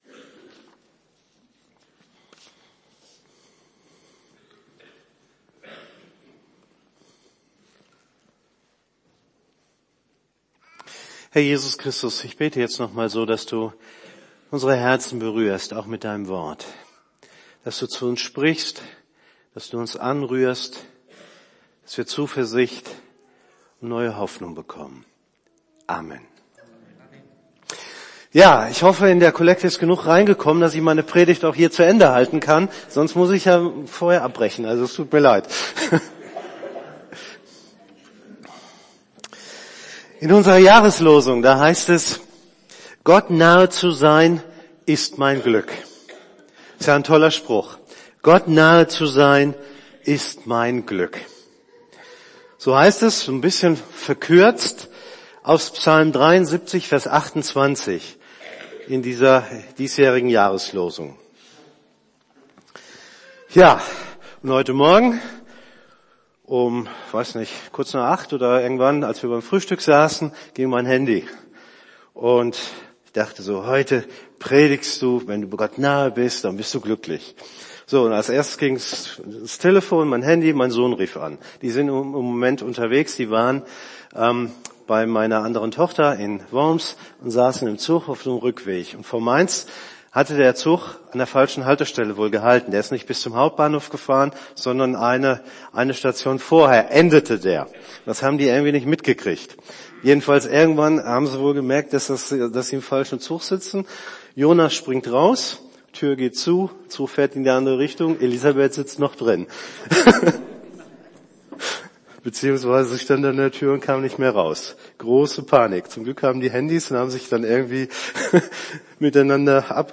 predigt.mp3